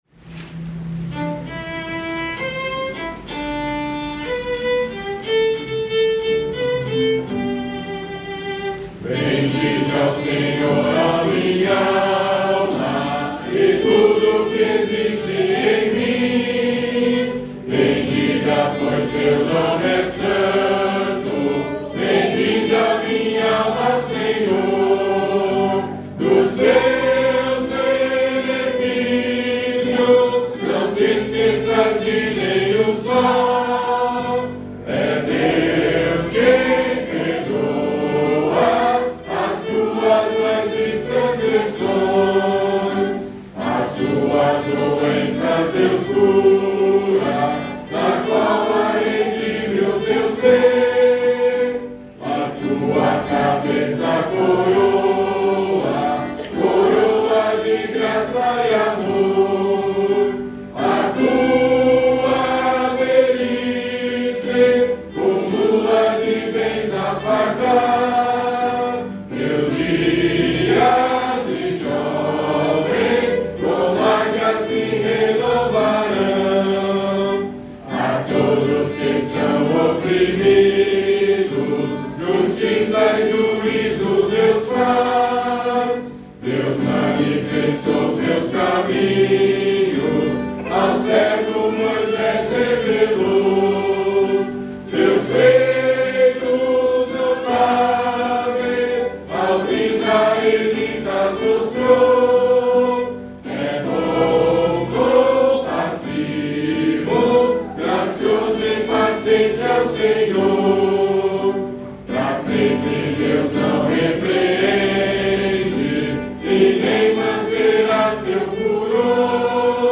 Compositor: William James Kirkpatrick, 1882
Metrificação: Comissão Brasileira de Salmodia, 2023
salmo_103B_cantado.mp3